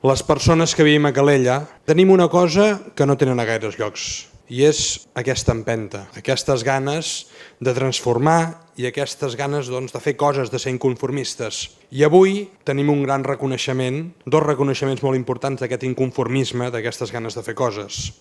En la cloenda de l’acte, l’alcalde Marc Buch va destacar l’empenta i l’esperit inconformista que, segons va dir, defineixen la ciutat i que es reflecteixen en els guardonats d’aquesta edició.